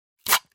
Звуки, возникающие при отклеивании разных липких предметов таких как: наклейки, скотчи, разные клейкие ленты, этикетки и ценники для монтажа видео.
1. Отклеили липкую ленту